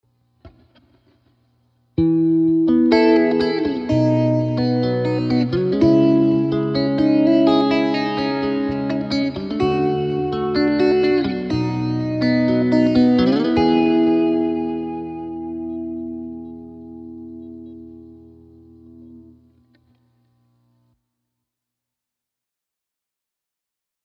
Clean (Squier Classic Vibe Tele 50’s)
Recording #2 with Graphic EQ, Chorus, Parametric EQ, Delay, Reverb, and Hi-pass filter applied